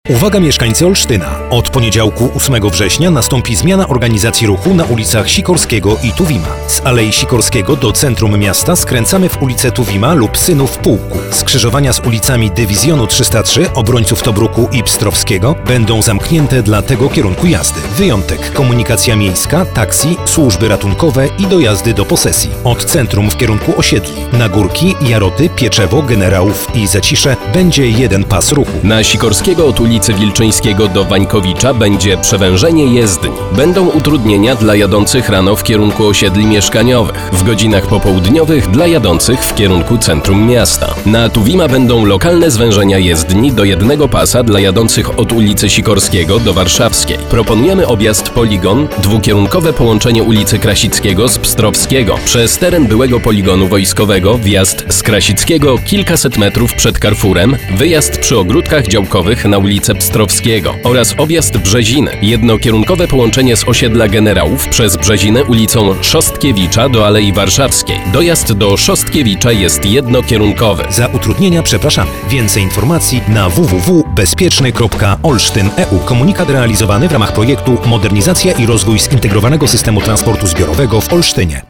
spot_dzwiekowy_utrudnienia_w_ruchu.mp3